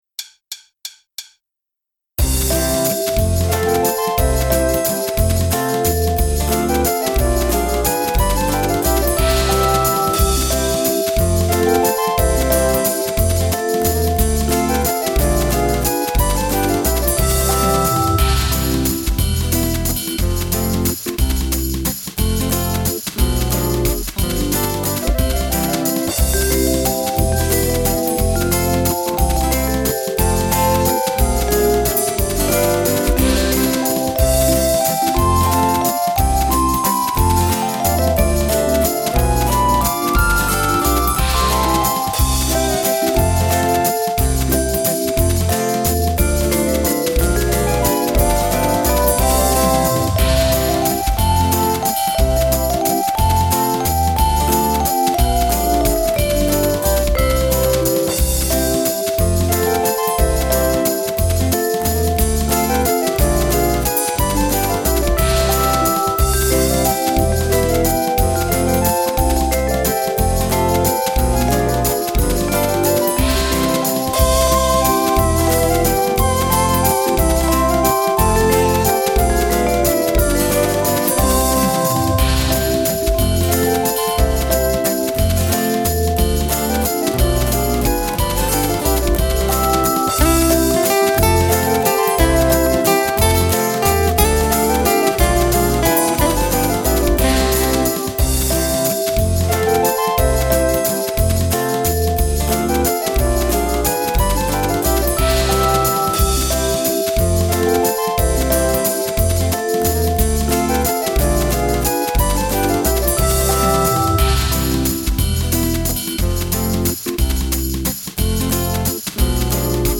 Valse Folk
Le Play Back
rythmique